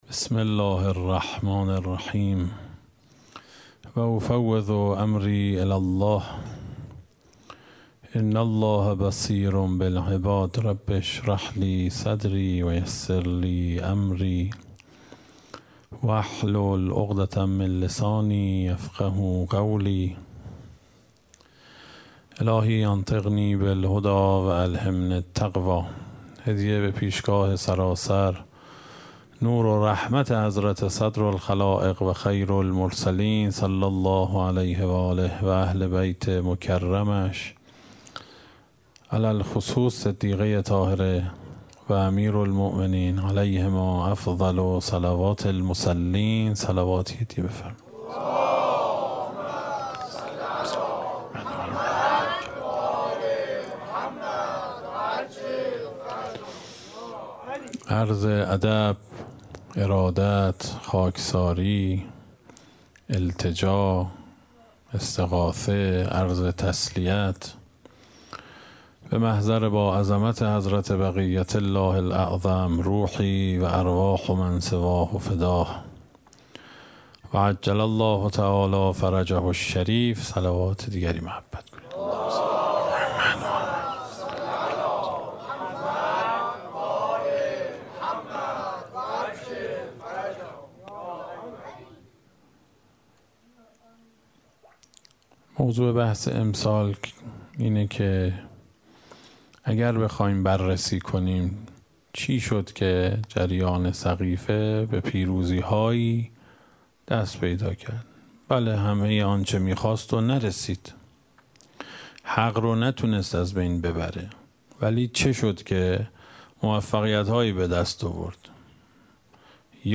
اشتراک گذاری دسته: الهیات شکست , حضرت فاطمه سلام الله علیها , سخنرانی ها قبلی قبلی الهیات شکست؛ فاطمیه اول – جلسه چهارم از ده جلسه بعدی الهیات شکست؛ فاطمیه اول – جلسه ششم از ده جلسه بعدی